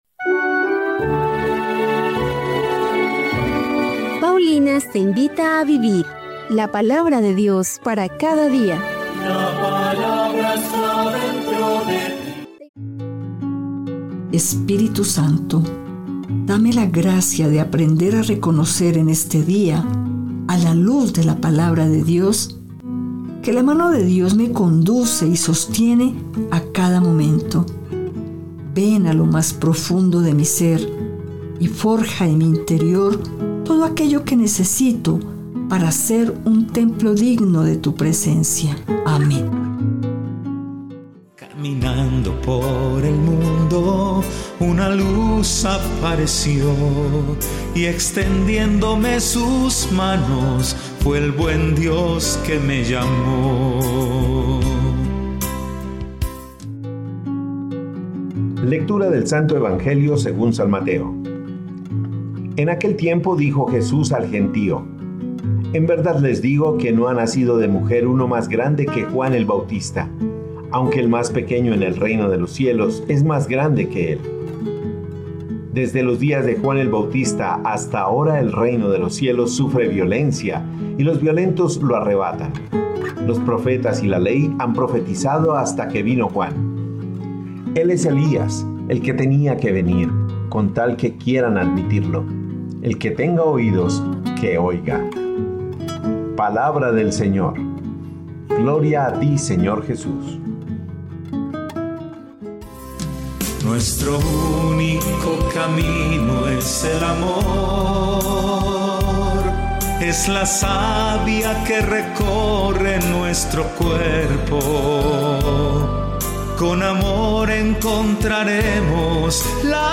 Liturgia diaria